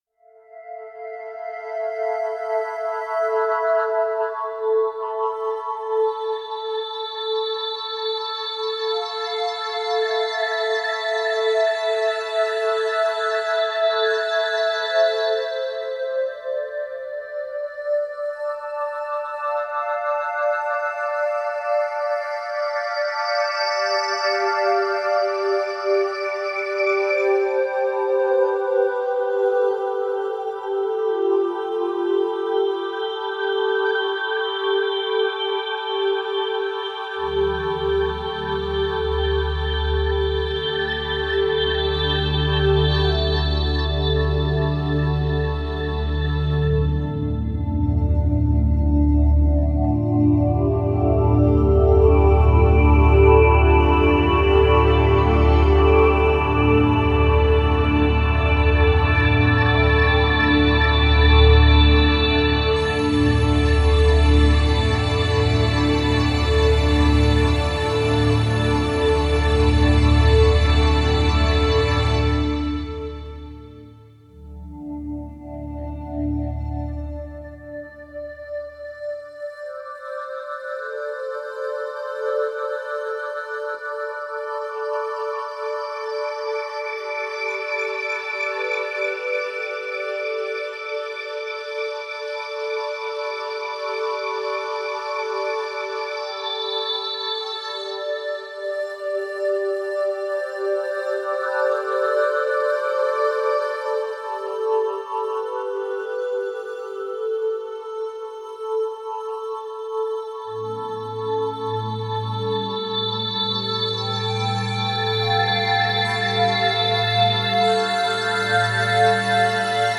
Медитативная музыка Духовная музыка